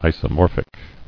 [i·so·mor·phic]
I`so*mor"phic , a. Isomorphous.